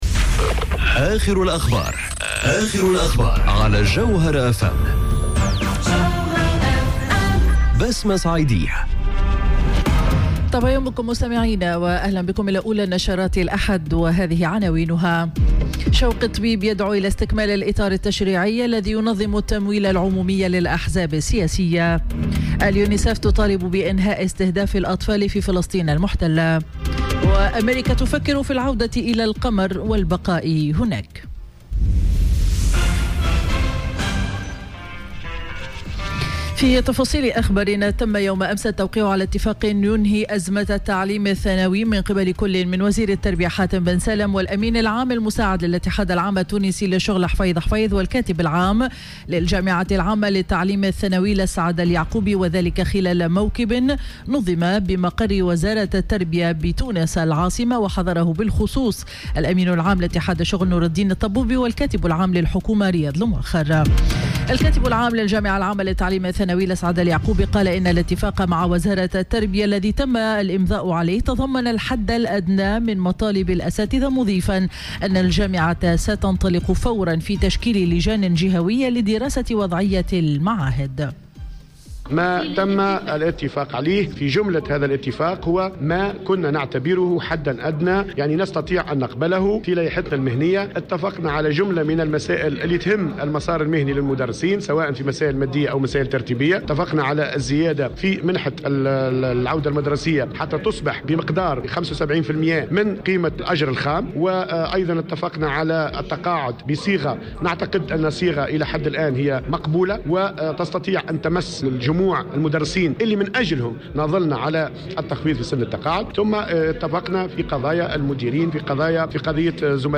Journal Info 07h00 du dimanche 10 Février 2019